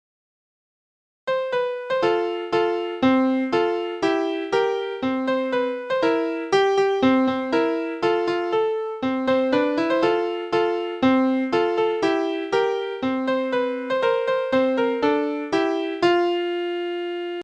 This song is sung in two parts.